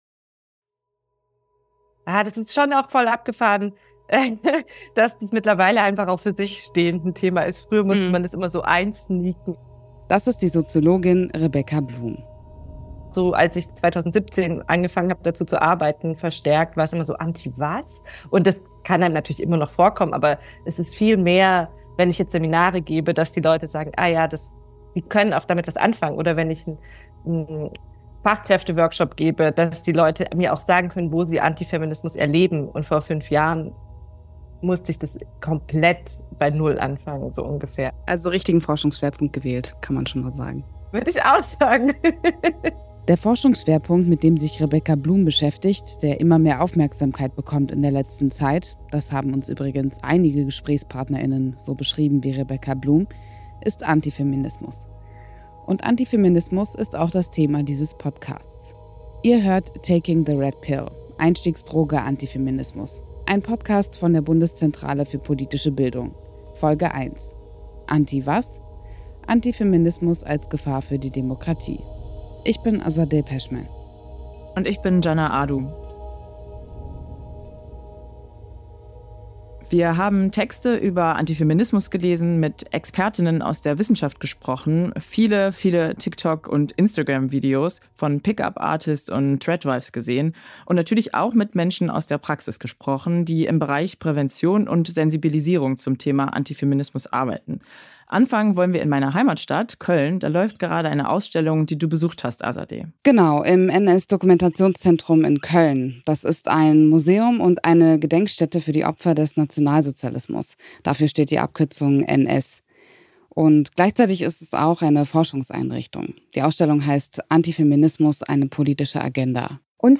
Soziologin
Bildungsreferentin